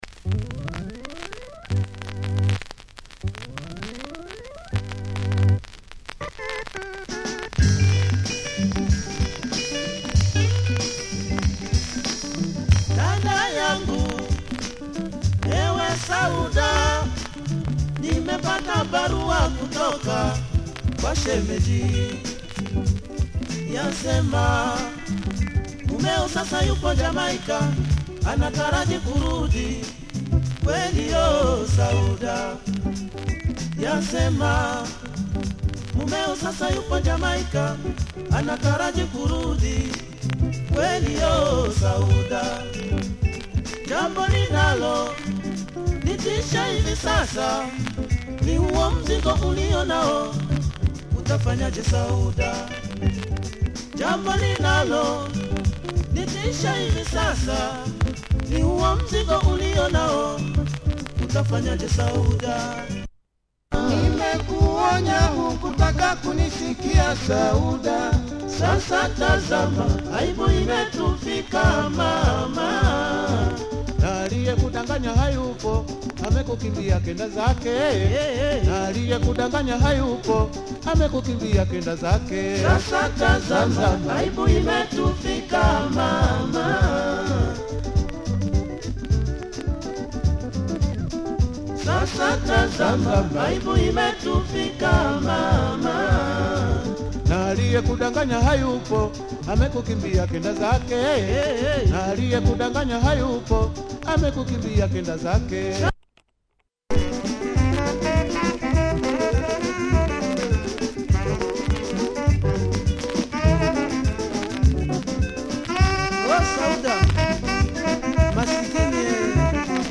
Sassy soukous